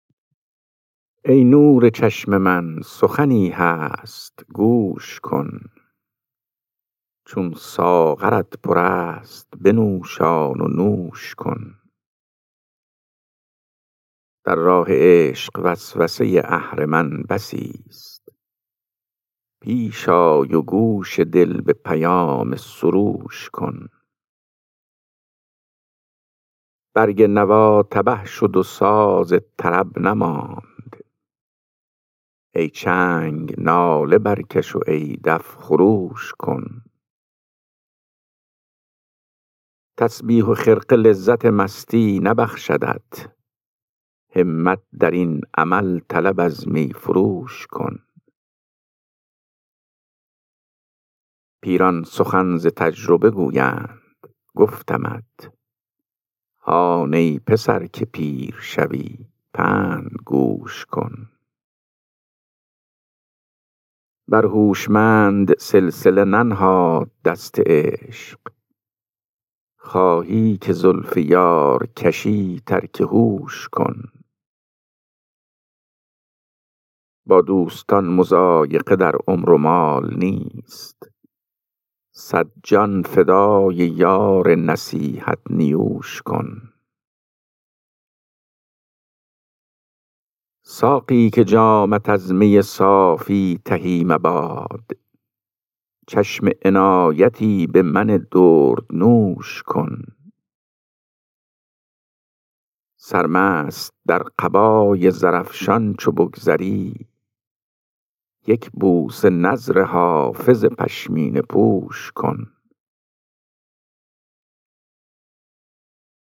خوانش غزل شماره 398 دیوان حافظ